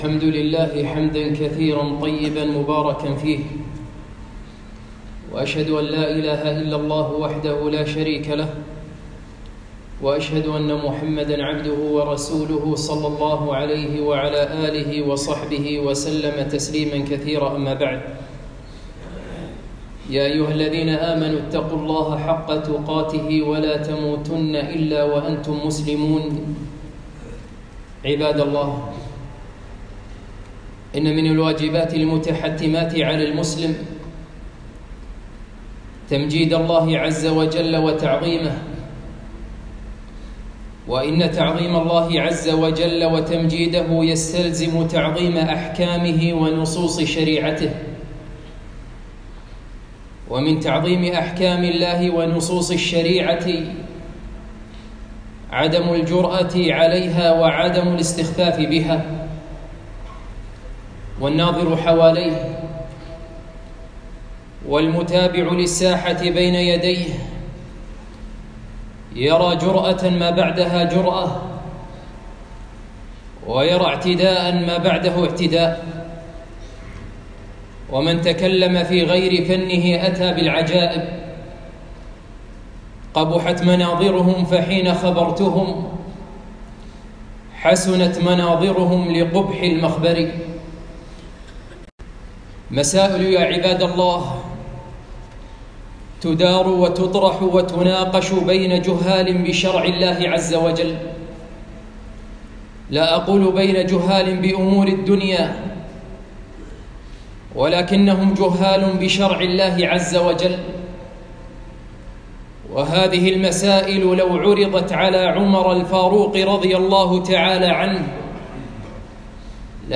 خطبة - من تكلم في غير فنه